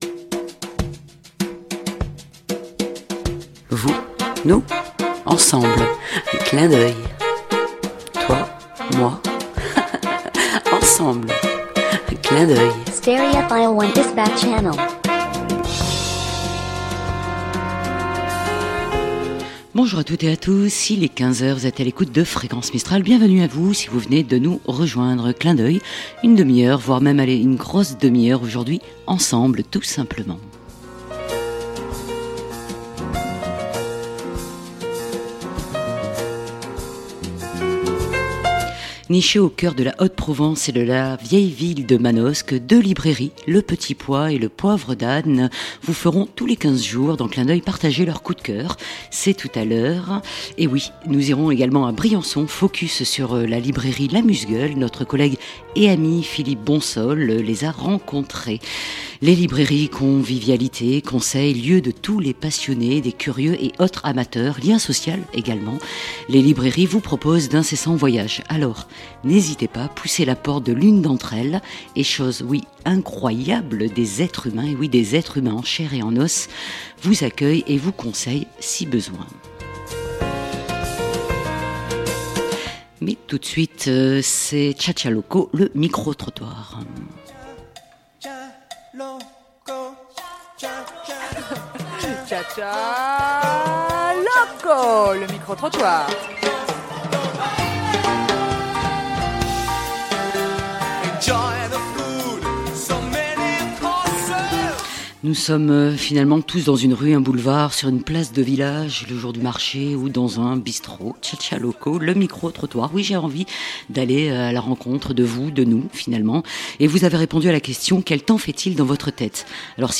Clin d'Oeil, être ensemble Tous les 15 jours le lundi à 15h Le micro trottoir local - régional Deux librairies Manosquines Le Petit Pois et Au Poivre d'Ane livrent leur coup de coeur Des rencontres et bien entendu de la musique !